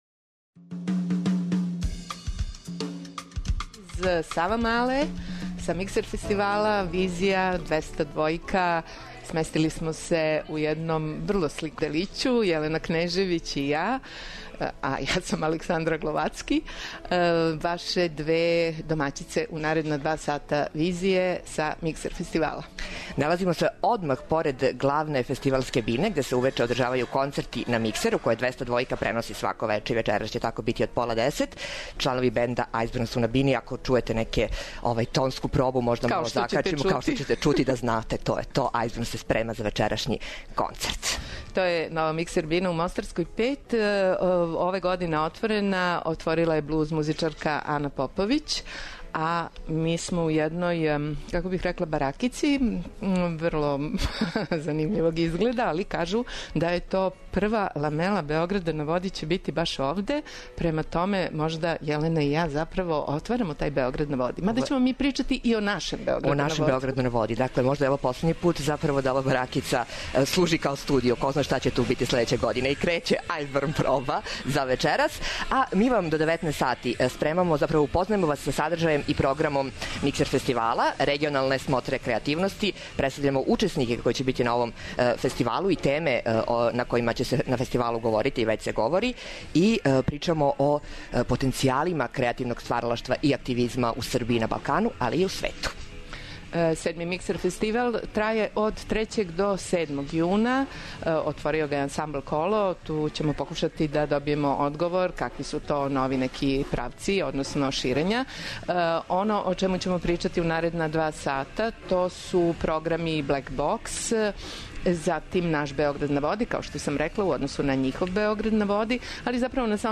Уживо из Савамале, са седмог Миксер фестивала, упознајемо вас са садржајем и програмом ове регионалне смотре креативности, представљамо учеснике и теме о којима се говори, разговарамо о потенцијалима креативног стваралаштва и активизма у Србији, на Балкану и свету данас.